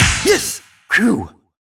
goodClap2.wav